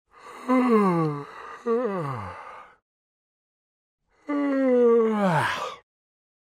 Звуки зевоты
Зевака мужик